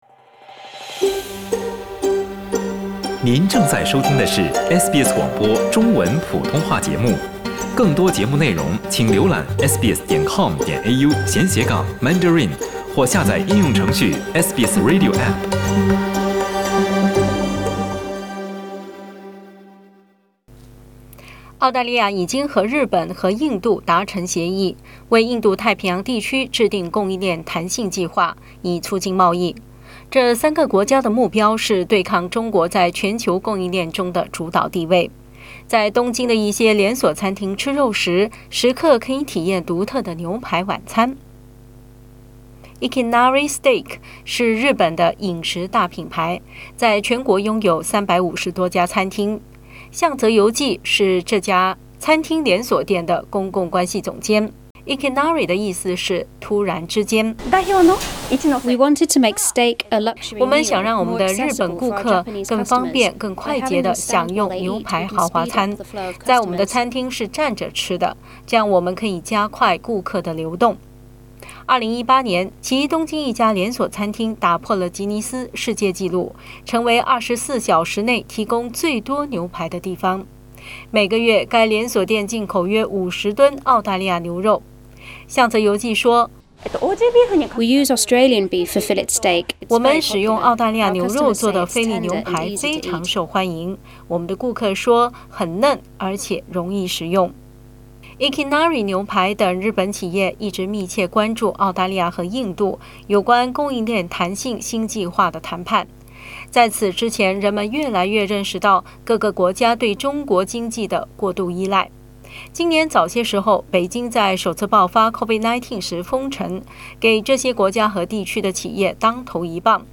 在过去的二十多年中，日本是澳大利亚牛肉的最大出口目的地，直到去年（2019）被中国取代。 点击图片收听详细报道。